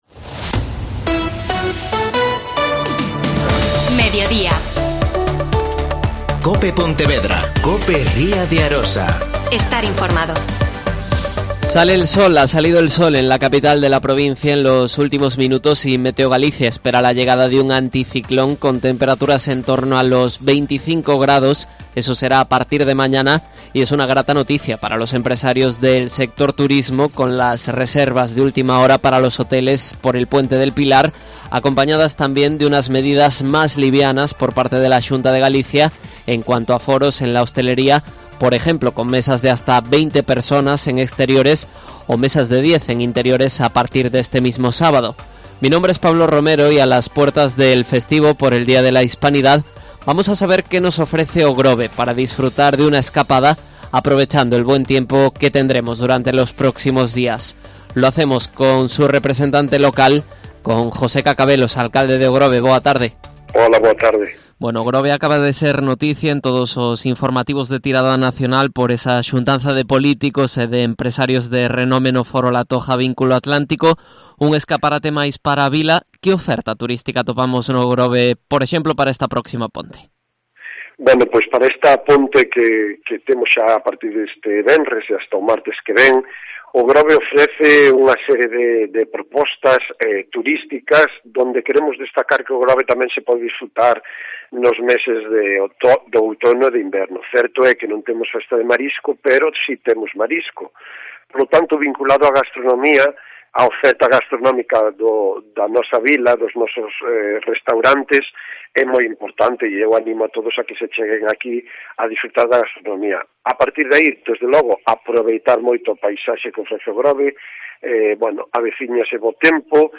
AUDIO: José Cacabelos. Alcalde de O Grove.